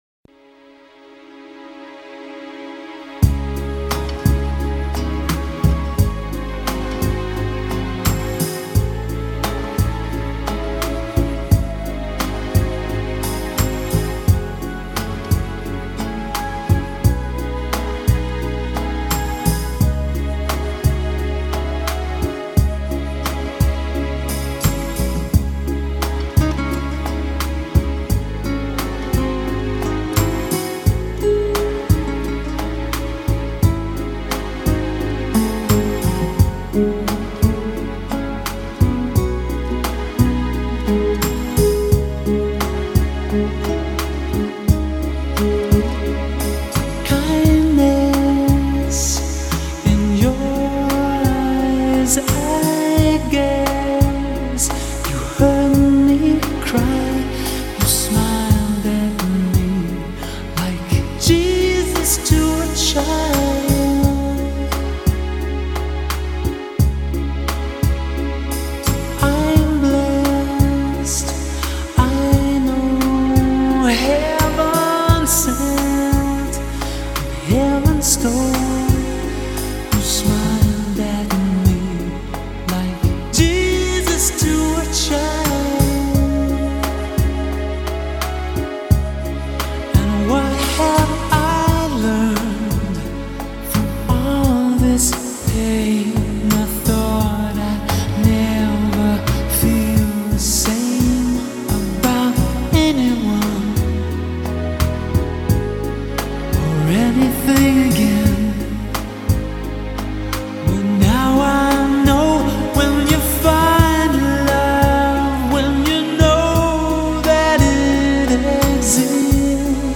зарубежные медляки , медленная музыка
медленные песни Размер файла